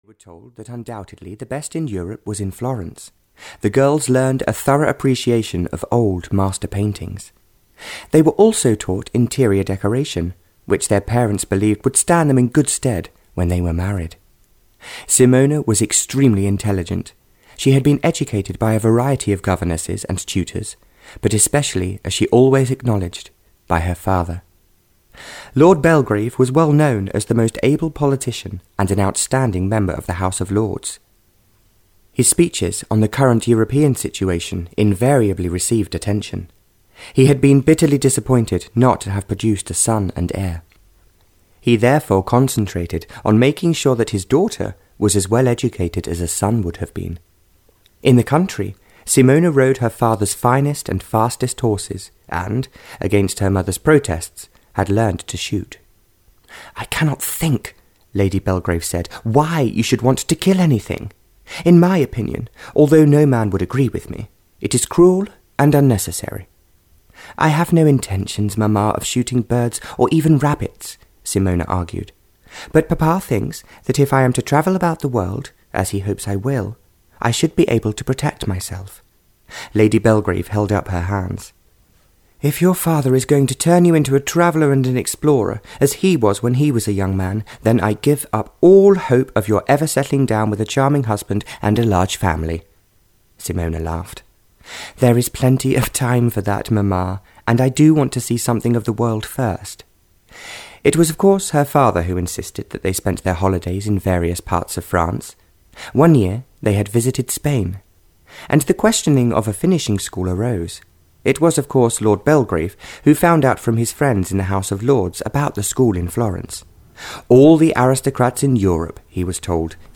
Audio knihaLove Wins in Berlin (Barbara Cartland’s Pink Collection 17) (EN)
Ukázka z knihy